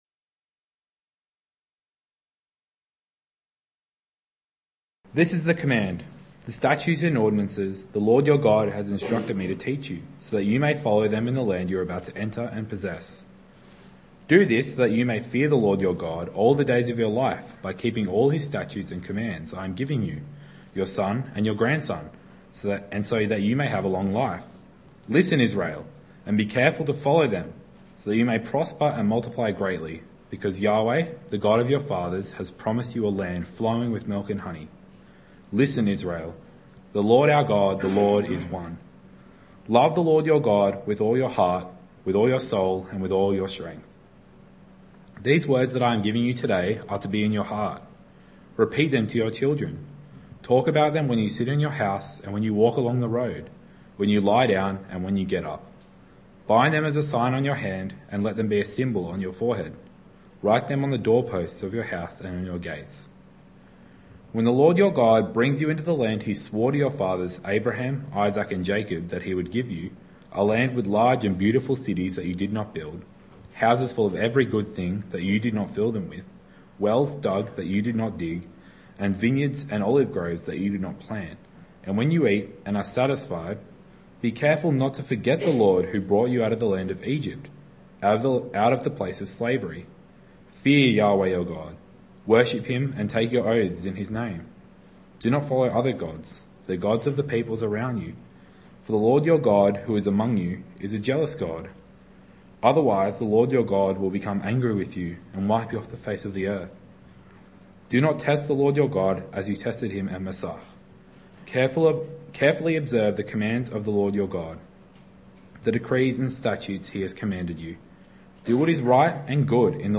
Evening Church